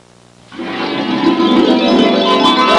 Harp Intro Sound Effect
Download a high-quality harp intro sound effect.
harp-intro.mp3